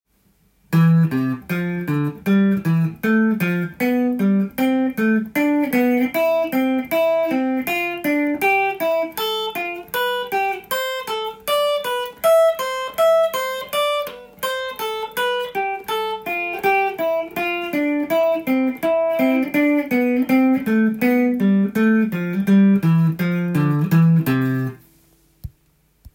度数ごとのCメジャースケール練習】
３度進行
ドからミ　レからファ　などが３度になります。